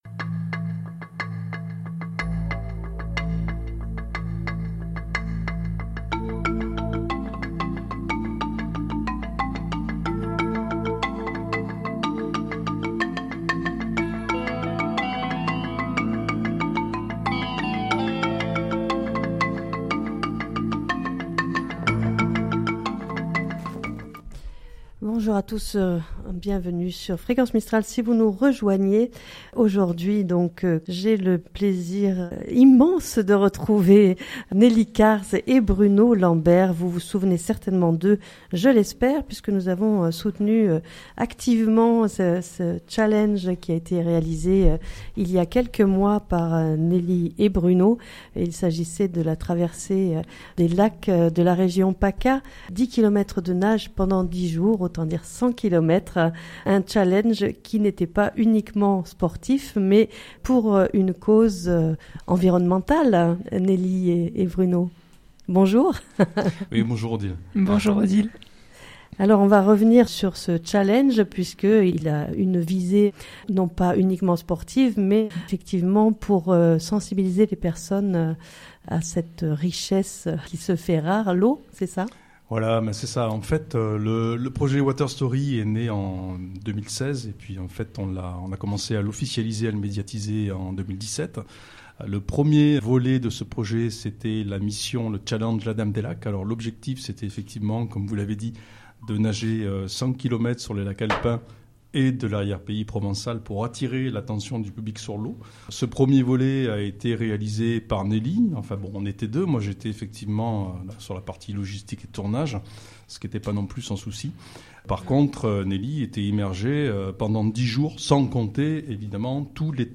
en direct sur Fréquence Mistral Digne 99.3